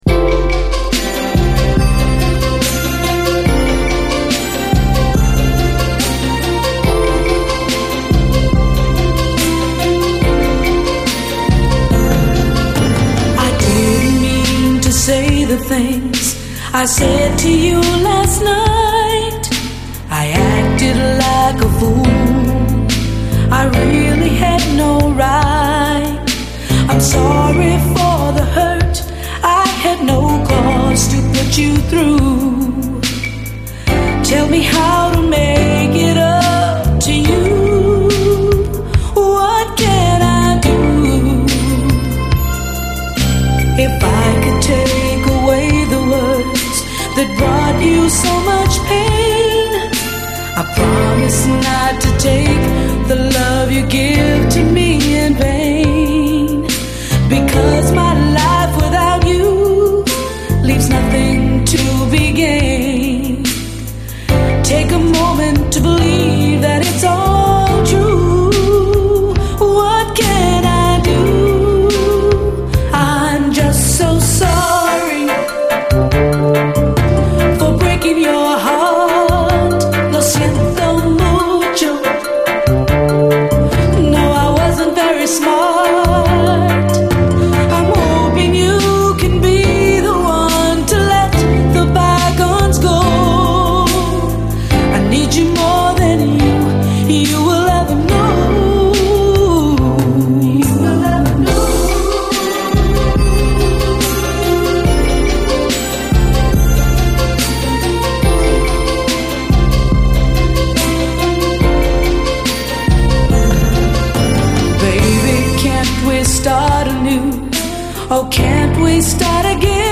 SOUL, 70's～ SOUL, LATIN